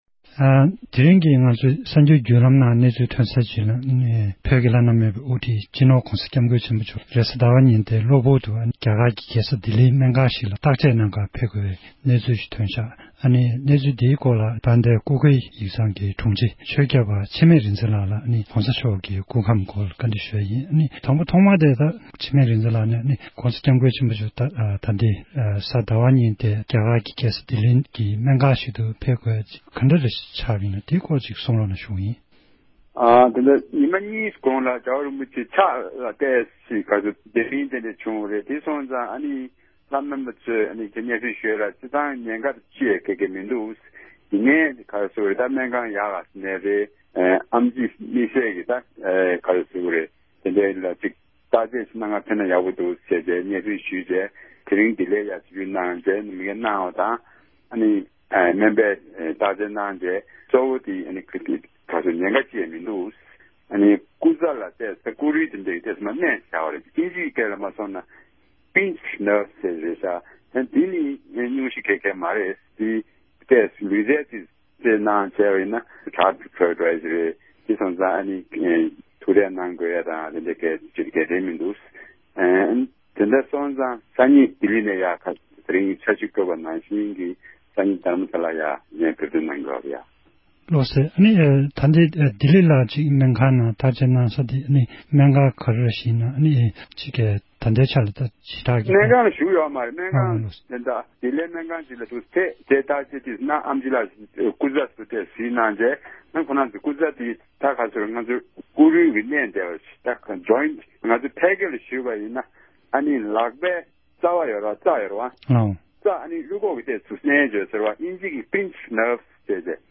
སྒྲ་ལྡན་གསར་འགྱུར།
གནས་འདྲི